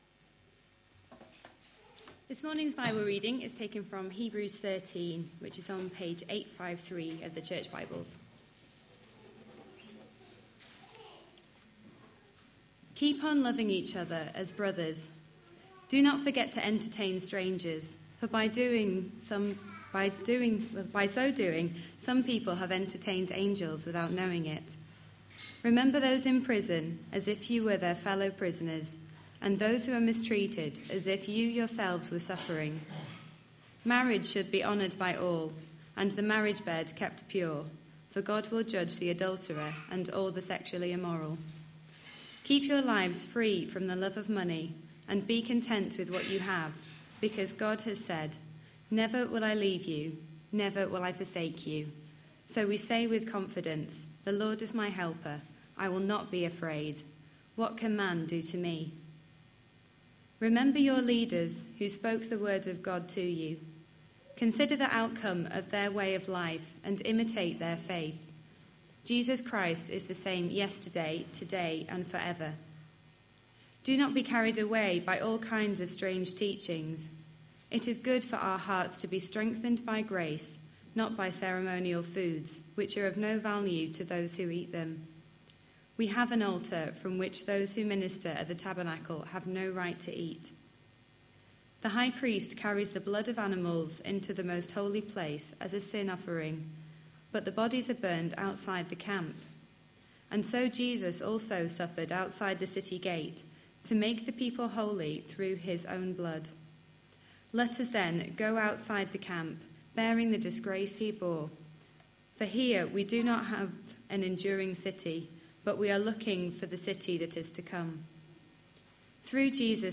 A sermon preached on 15th March, 2015, as part of our Hebrews series.